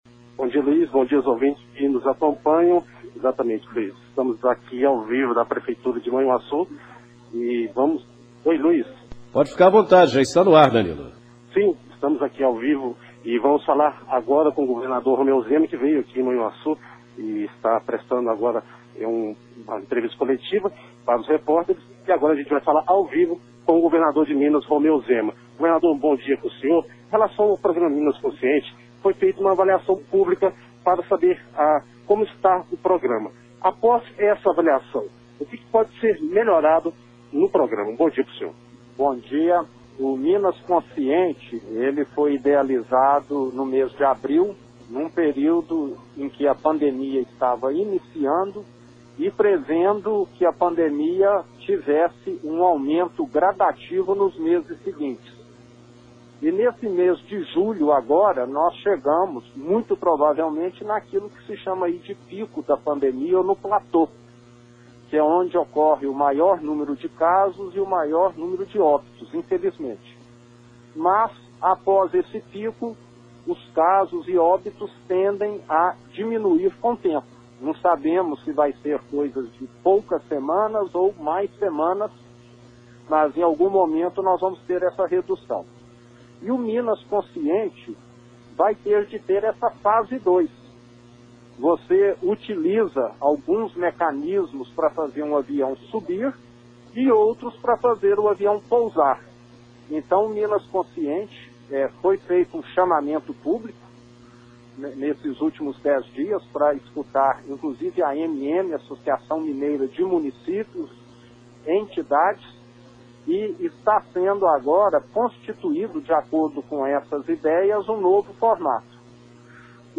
Durante entrevista para a Rádio Manhuaçu AM 710 ele destacou a respeito de mudanças no programa Minas Consciente.
ENTREVISTA-RÁDIO-MANHUAÇU-ZEMA-24_07.mp3